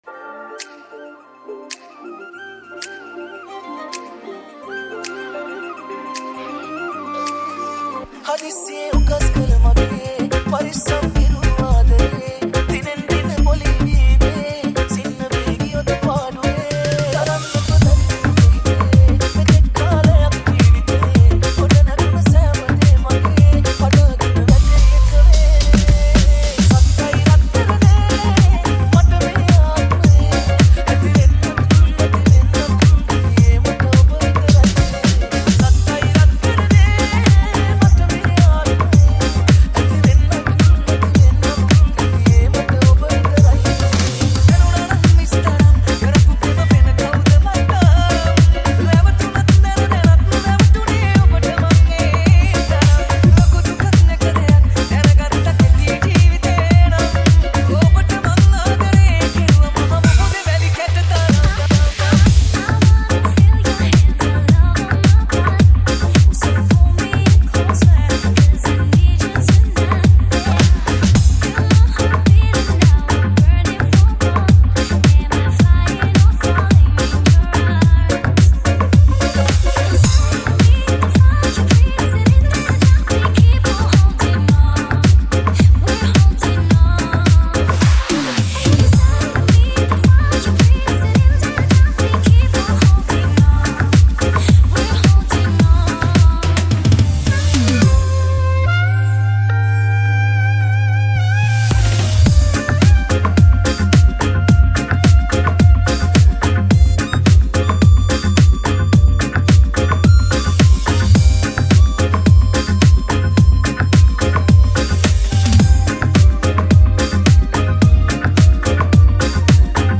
High quality Sri Lankan remix MP3 (7).